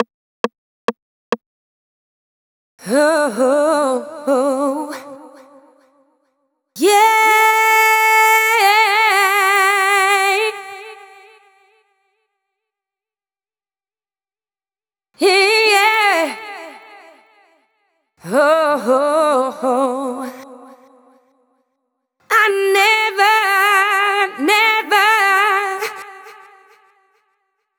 Adlibs 2 FX.wav